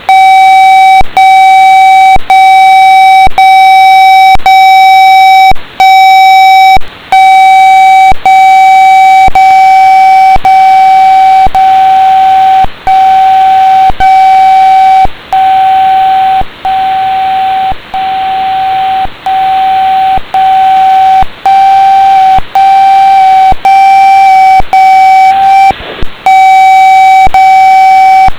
Тональник на 124.000 МГц.
16 сентября среди белого дня два раза звучал такой тональный сигнал. Происхождение неизвестно.